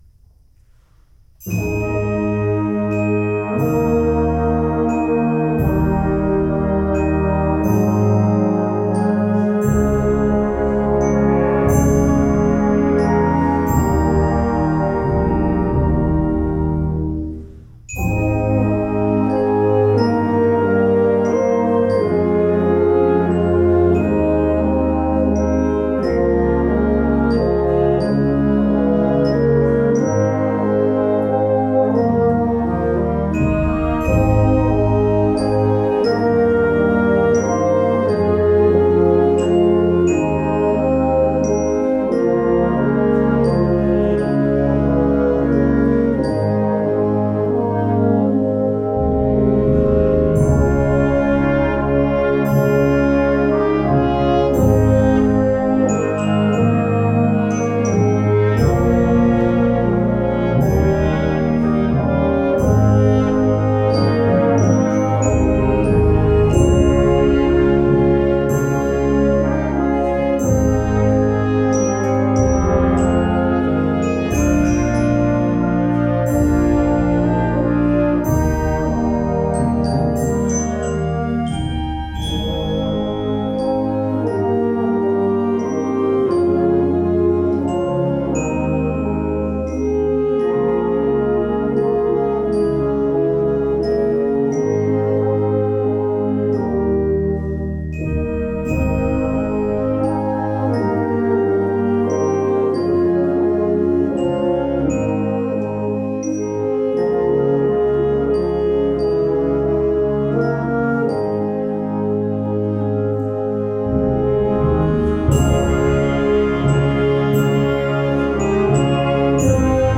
Concert Band
lyric tune